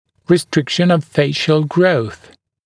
[rɪ’strɪkʃn əv ‘feɪʃl grəuθ][ри’стрикшн ов ‘фэйшл гроус]ограничение роста лица